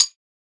edm-perc-02.wav